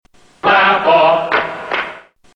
Tags: The Clapper The Clapper clips The Clapper sounds The Clapper ad The Clapper commercial